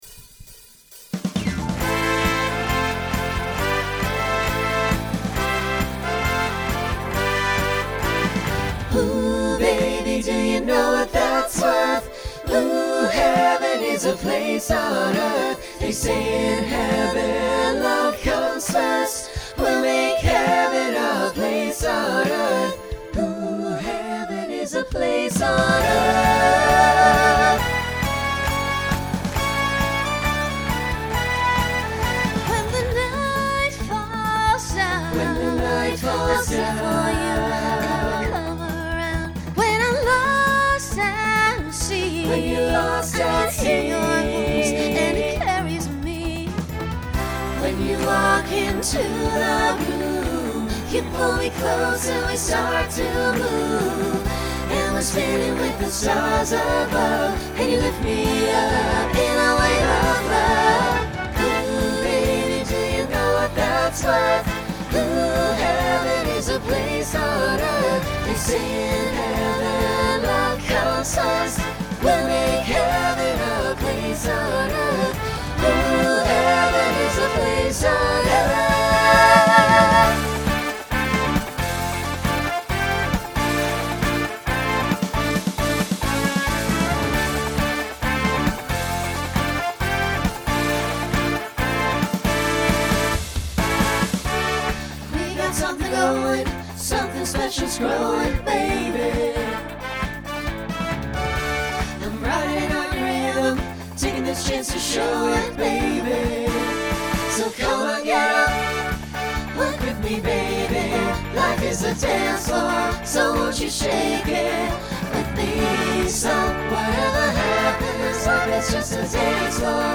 Genre Pop/Dance
Voicing SATB